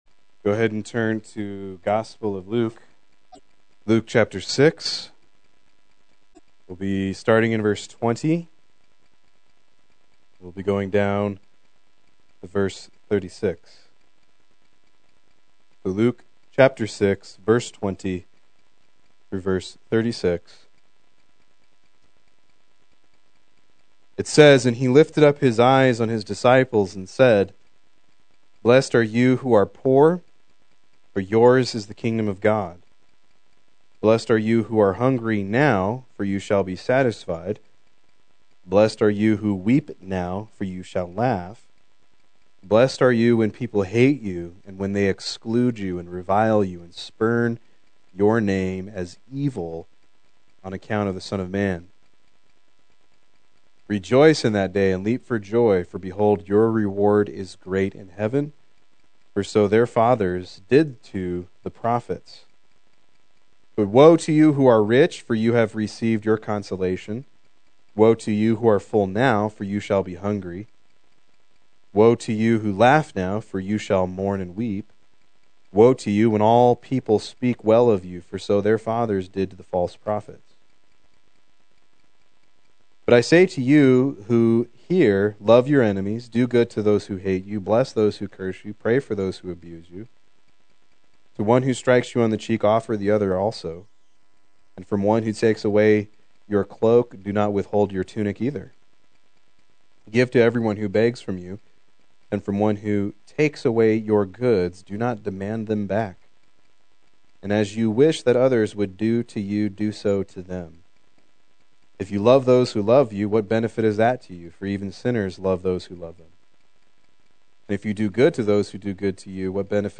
Proclaim Youth Ministry - 07/13/18
Play Sermon Get HCF Teaching Automatically.